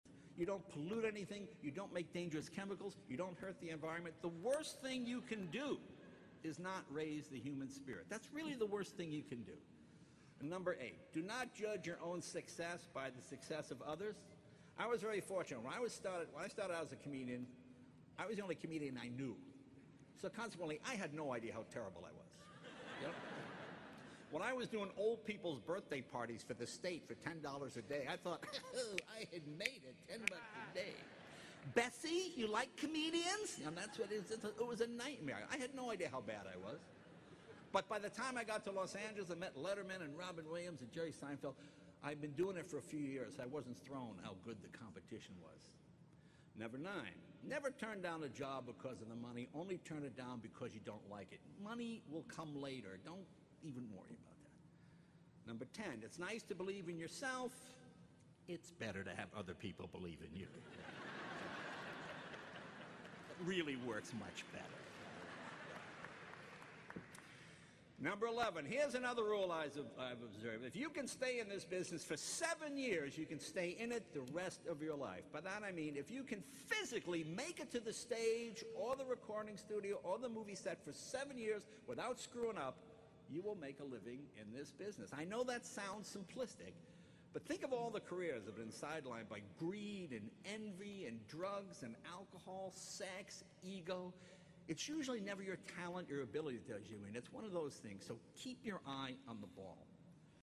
公众人物毕业演讲 第211期:杰雷诺2014爱默生学院(9) 听力文件下载—在线英语听力室